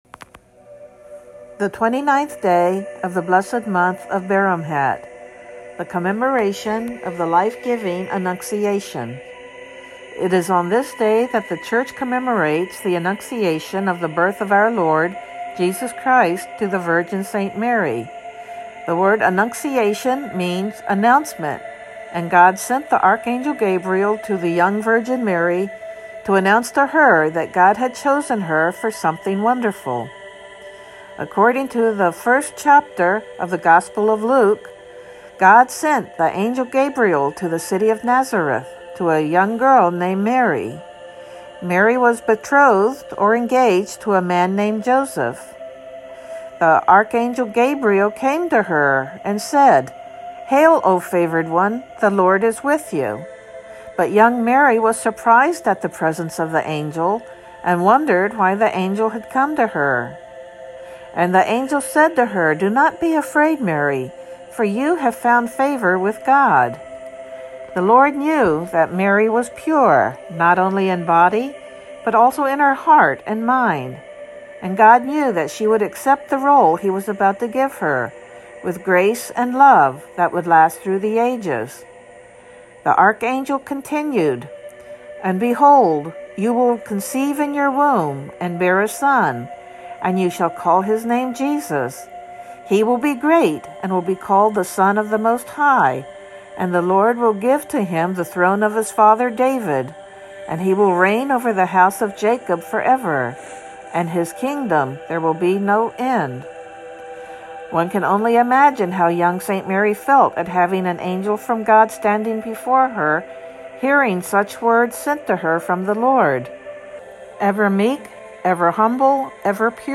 Synaxarium reading for 29th of Baramhat